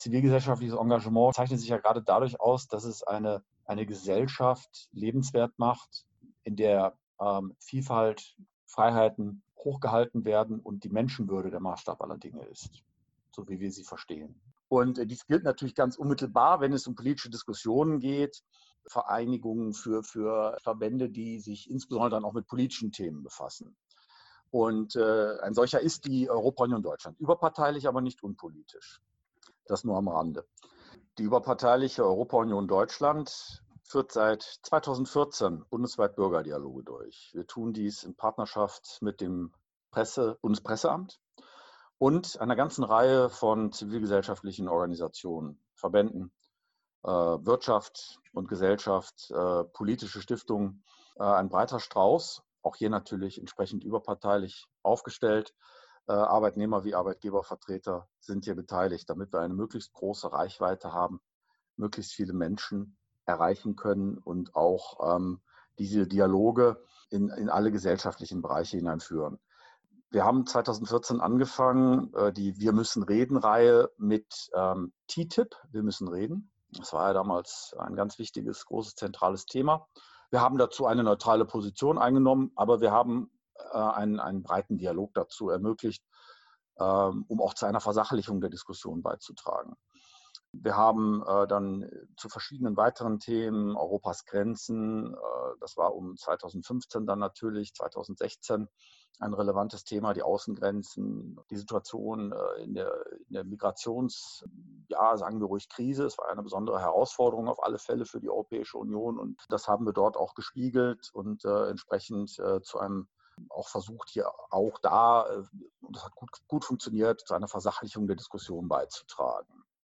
Audiointerview